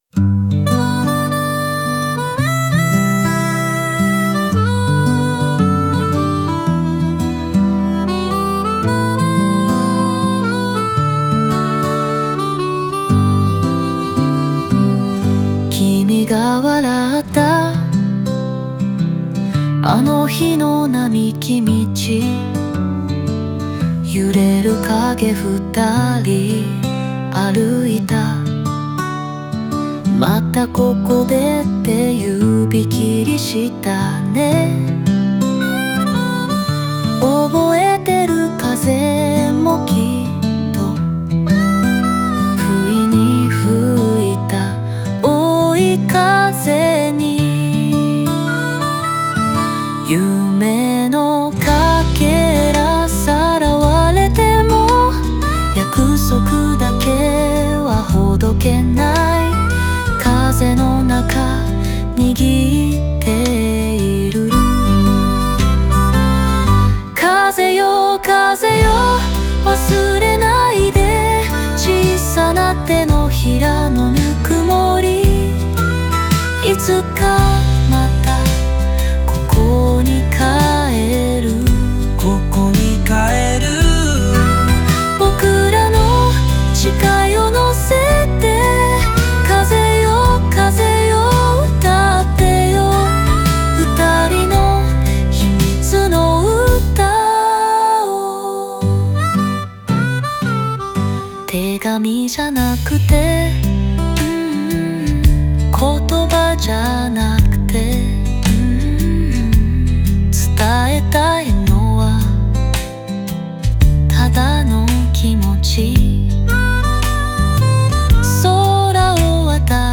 風の音や自然の要素が、歌詞とメロディにやさしさを加え、聴く人に温かさと切なさを届けます。